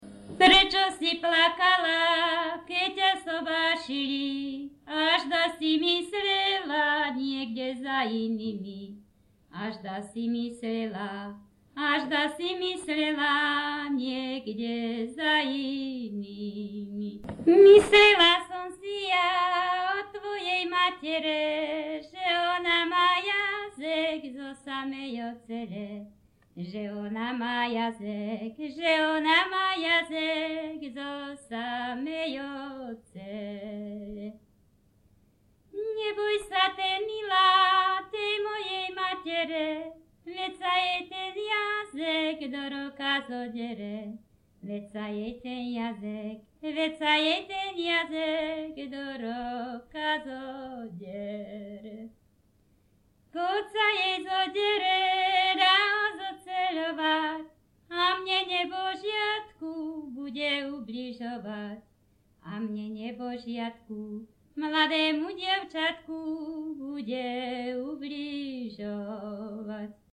Popis sólo ženský spev bez hudobného sprievodu
Všeobecný popis Pieseň sa spievala na svadobnej hostine počas svadobnej zábavy alebo „za stolámi“.
Miesto záznamu Litava
Kľúčové slová ľudová pieseň
svadobné piesne